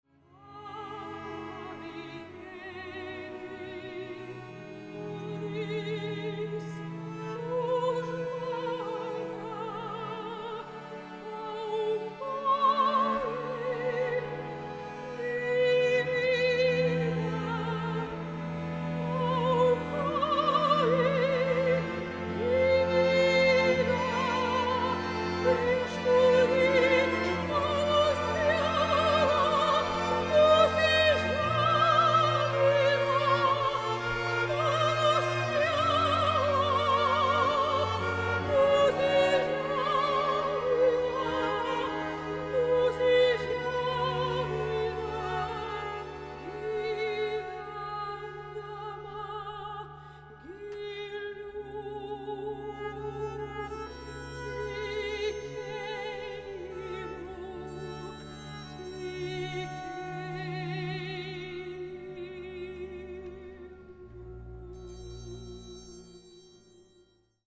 sopranas / soprano
mecosopranas / mezzosoprano
smuikas / violin
altas / viola
viola da gamba
barokinė violončelė /Baroque cello
kontrabosas / bass
kanklės
vargonai / organ
mušamieji / percussion